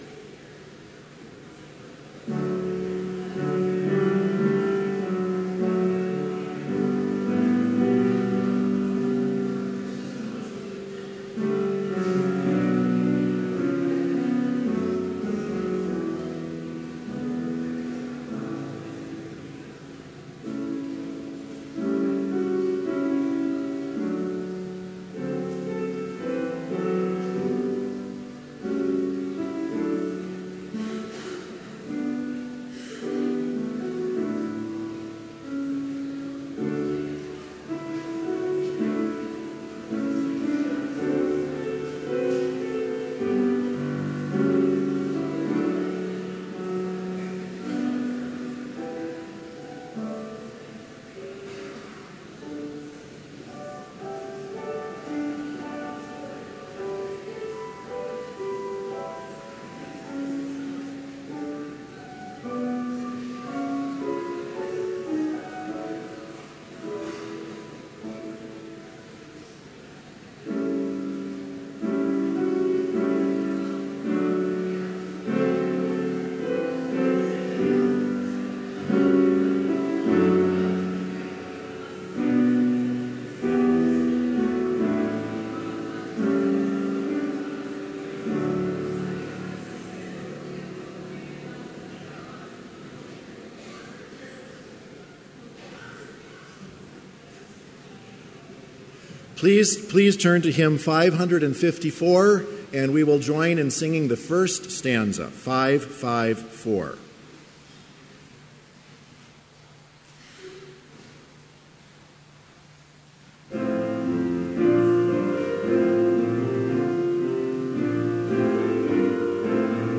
Complete service audio for Chapel - November 1, 2016